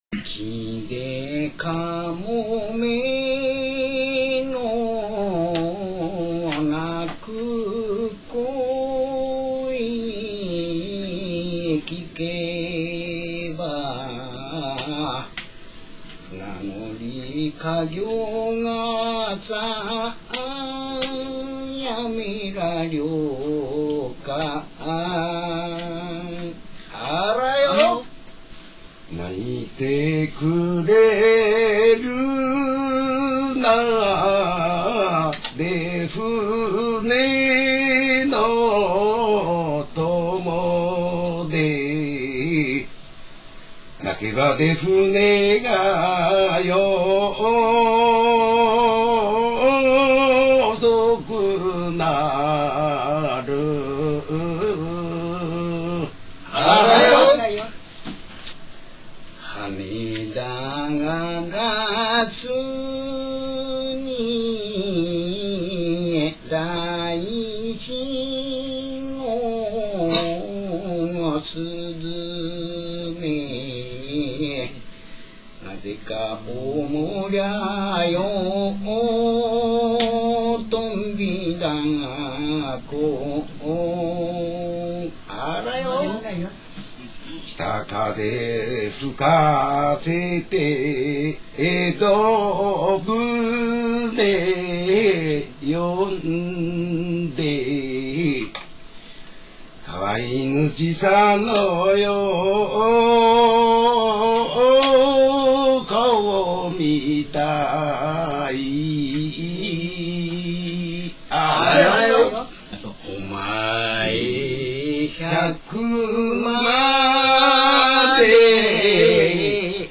『五大力船の最後の船頭』の方々のお話(昭和４８年録音)から、当時の姉崎の五大力船の様子をご紹介致します。